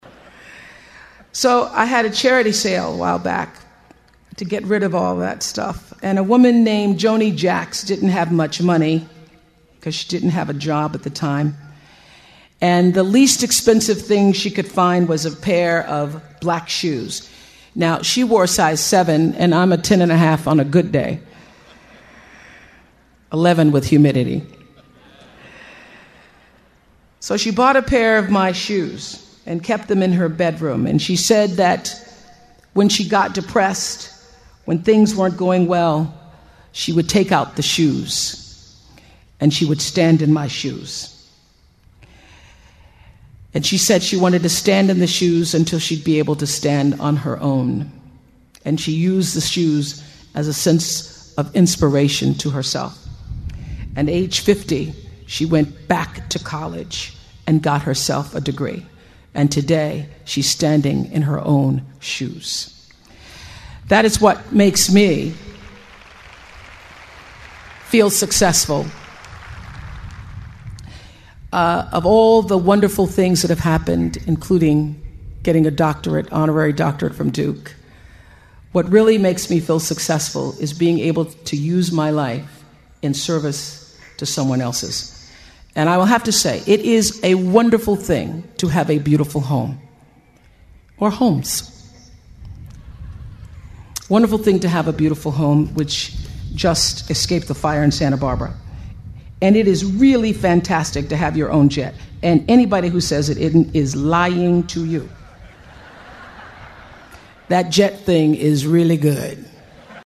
借音频听演讲，感受现场的气氛，聆听名人之声，感悟世界级人物送给大学毕业生的成功忠告。